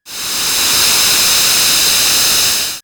GasReleasing06.wav